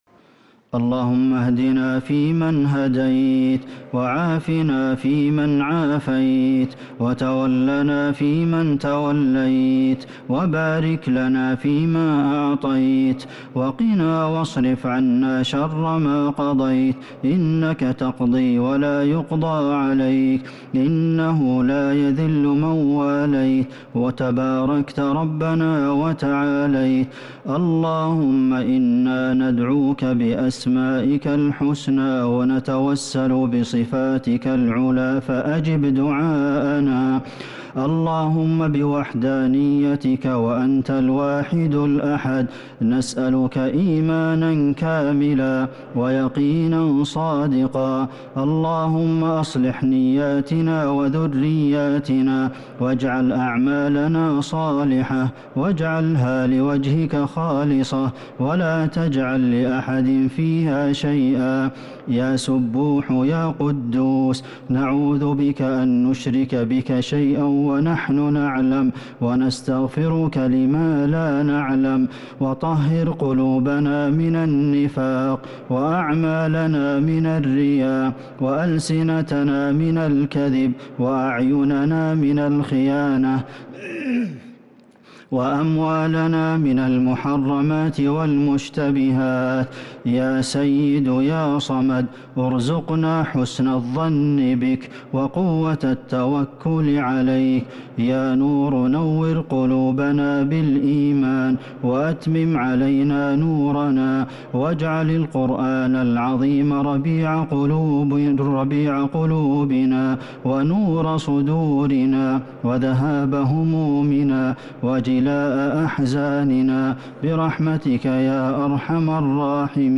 دعاء القنوت ليلة 11 رمضان 1443هـ | Dua for the night of 11 Ramadan 1443H > تراويح الحرم النبوي عام 1443 🕌 > التراويح - تلاوات الحرمين